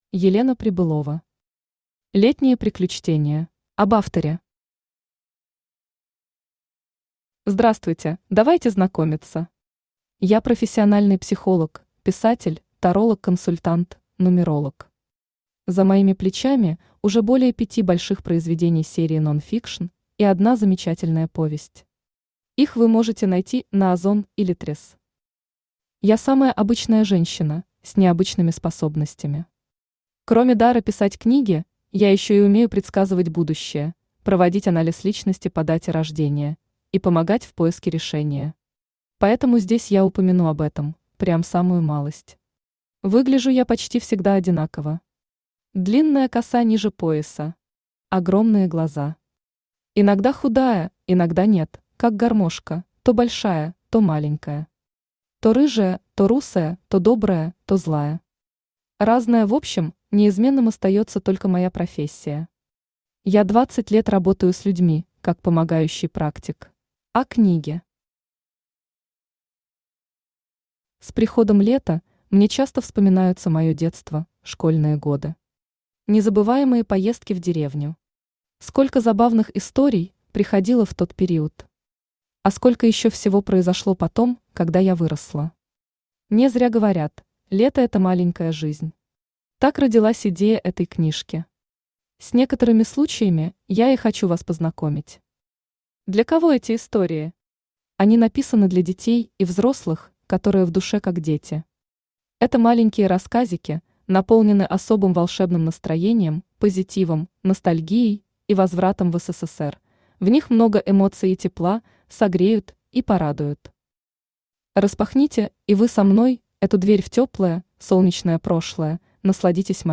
Aудиокнига Летние приключтения Автор Елена Михайловна Прибылова Читает аудиокнигу Авточтец ЛитРес.